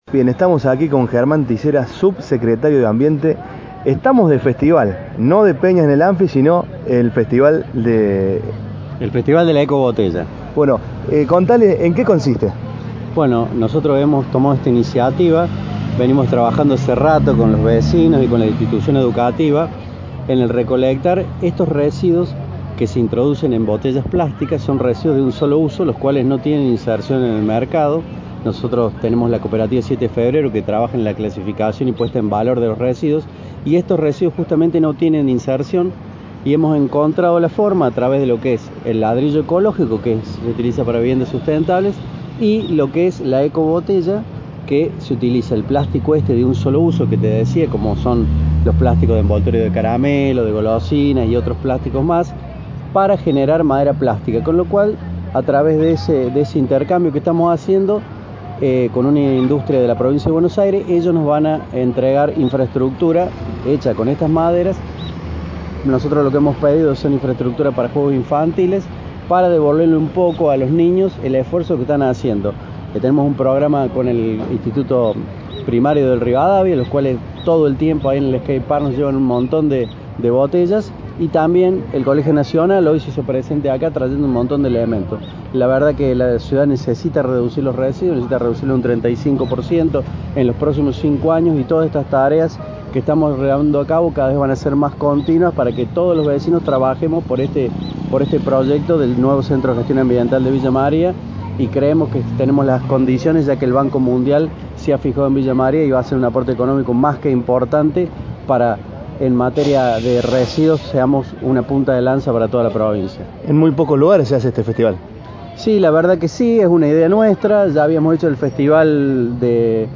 Este viernes se llevó a cabo el Festival de Ecobotellas, donde se intercambiaban botellas plásticas por plantines de arbustos, plantas y frutas. Allí dialogamos con Germán Tissera, Secretario de Ambiente del municipio sobre la actividad desplegada.